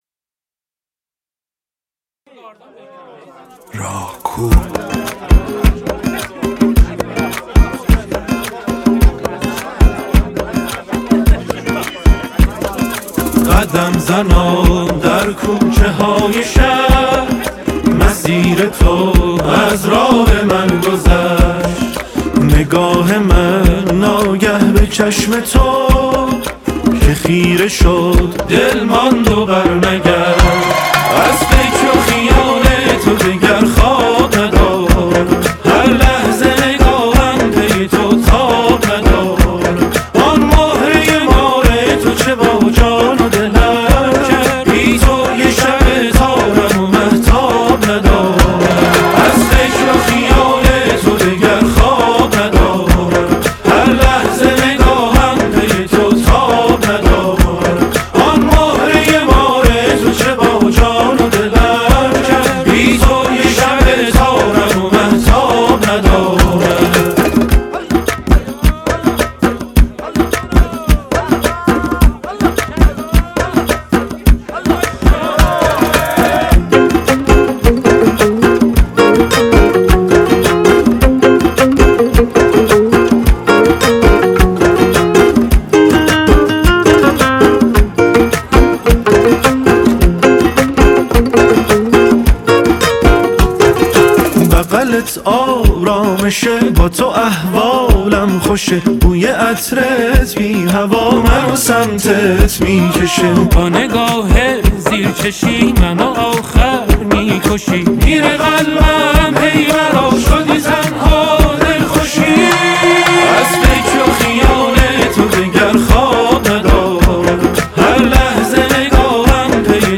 با صدای خاص و ملودی گیراش